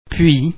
The French [u+vowel ] sound is actually the French [ u ] sound pronounced before an [ i ], [ y ], [e] or [a] but perhaps shorter and more like just a transition sound.